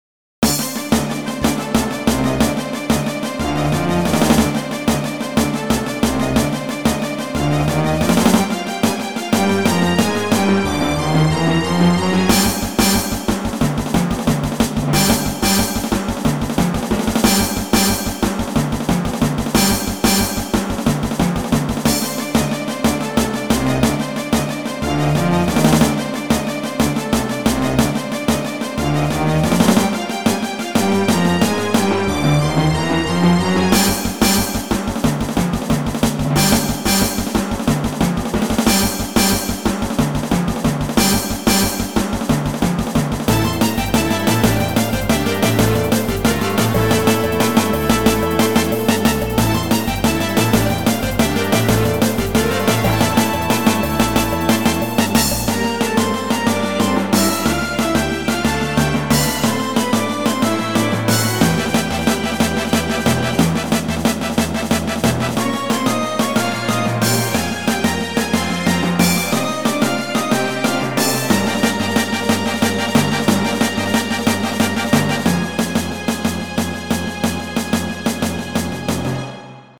拍子がいまいち不明なのに曲になってるってのがすごいですね。